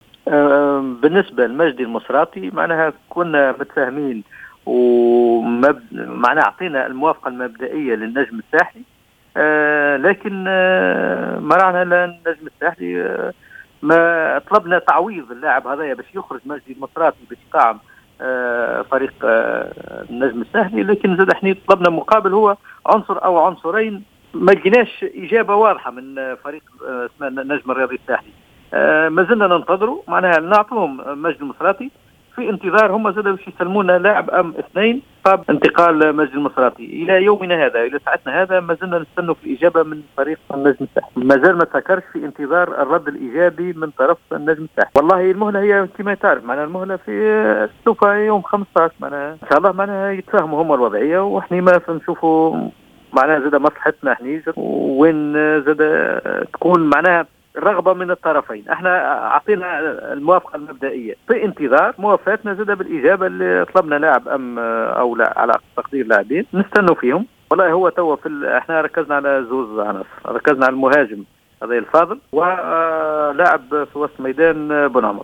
و في ندوة صحفية